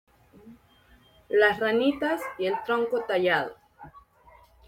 Audio libro (La ranitas y el tronco tallado)